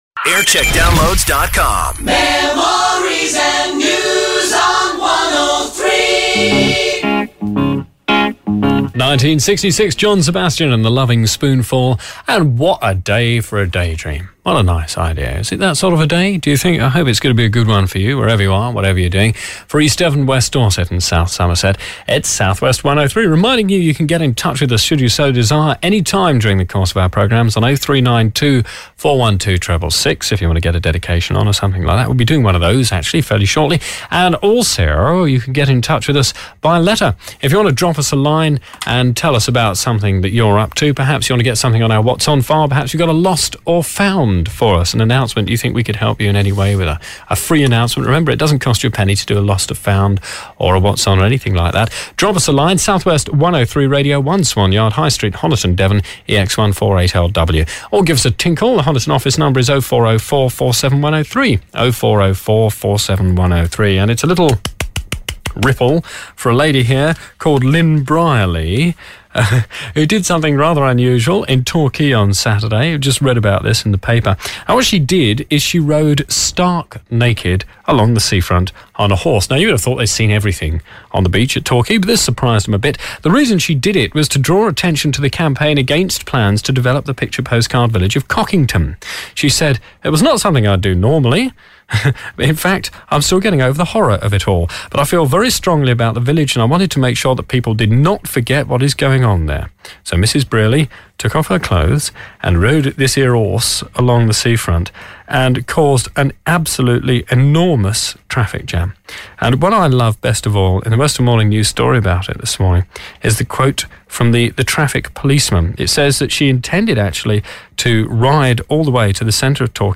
Jingle Montage